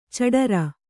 ♪ caḍara